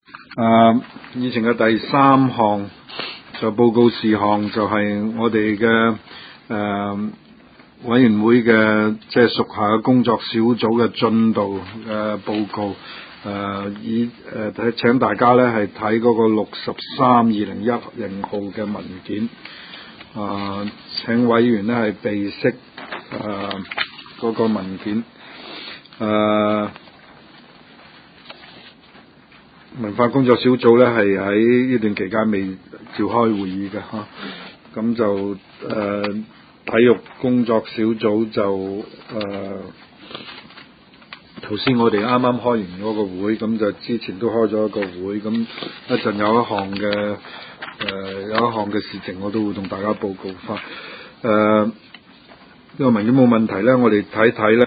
文化及康體事務委員會第十七次會議
灣仔民政事務處區議會會議室